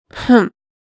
sigh.wav